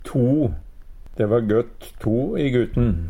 to - Numedalsmål (en-US)